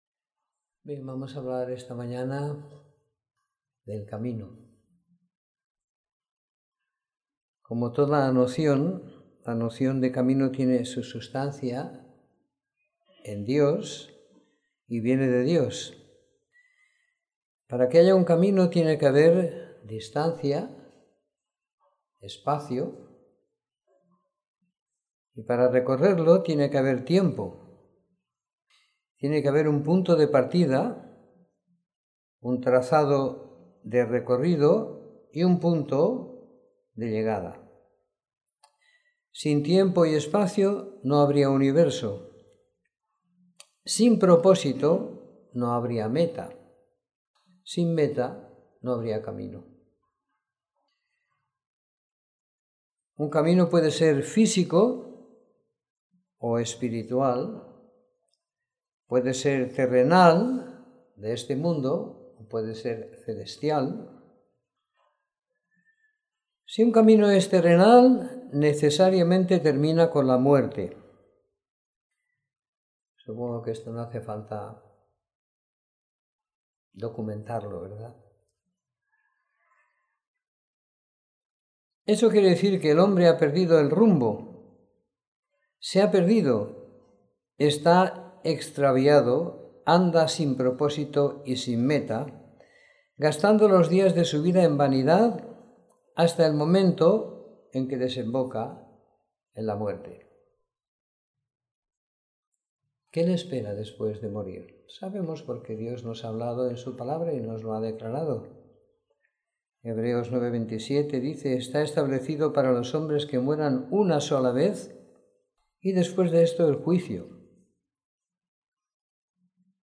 Reunión semanal de Predicación del Evangelio